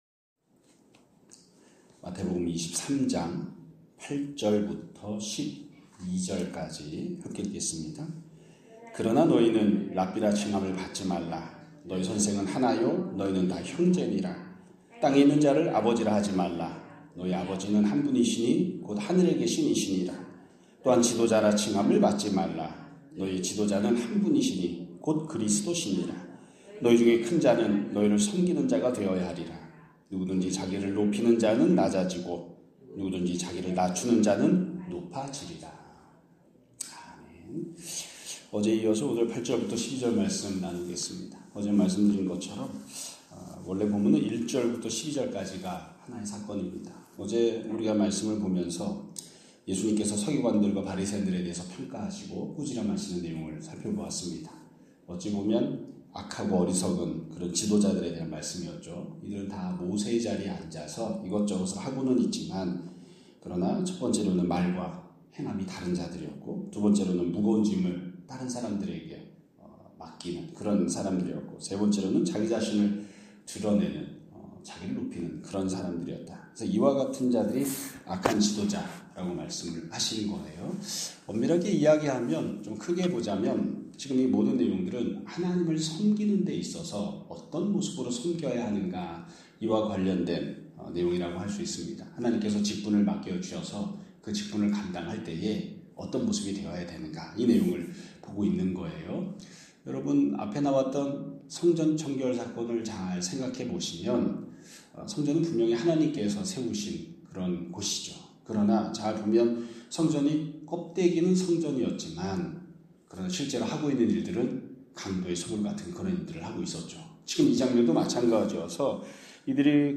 2026년 2월 20일 (금요일) <아침예배> 설교입니다.